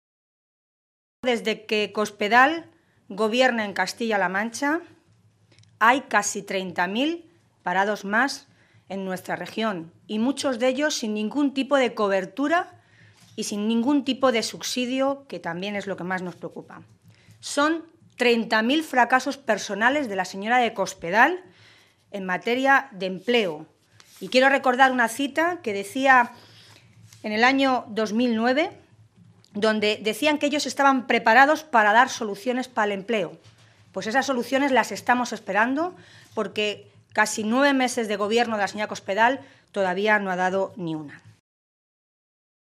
Milagros Tolón, diputada regional del PSOE de Castilla-La Mancha
Cortes de audio de la rueda de prensa